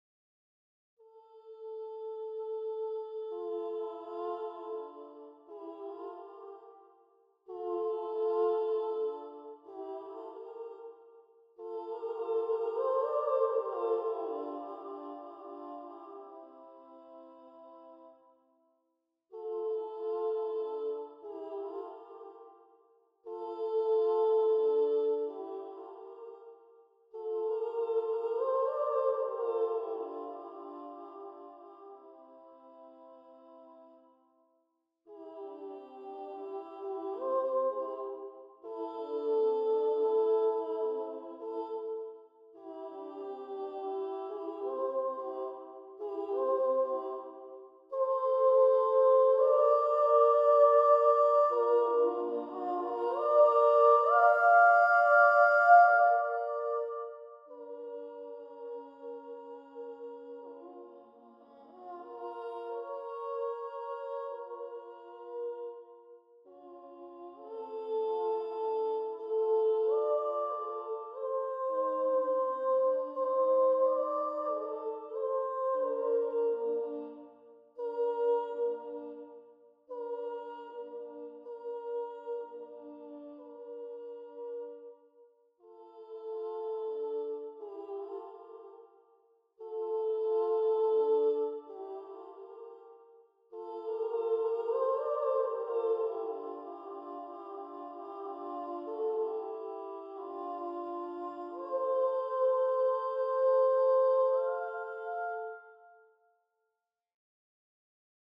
Her tone is just great, right on key, and free of that nasty vibrato that (to my mind) mars so much of trained singing, and doubly so for sopranos.
I used some sampled instruments and sound effects for accompaniment and variety.
Her singing here is wordless, but all the songs originally had words in Latin, German, English, Portuguese, and French.